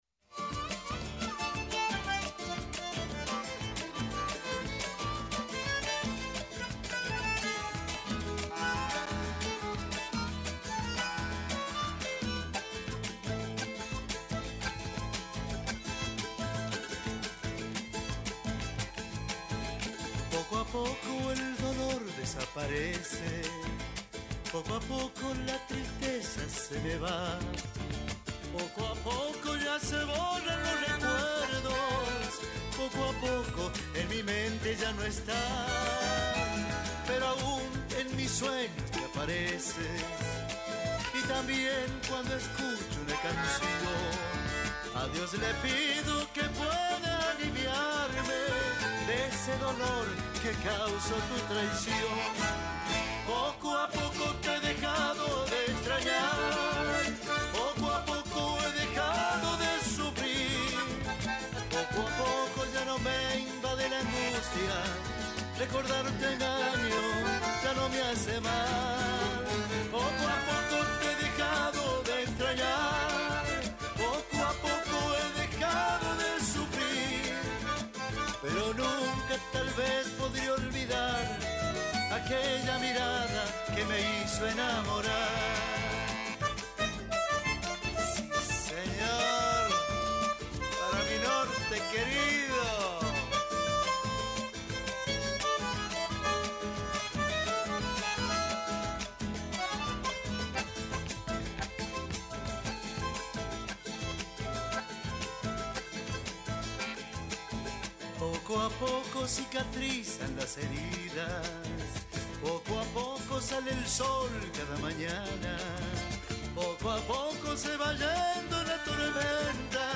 TOCANDO EN VIVO EN NUESTROS ESTUDIOS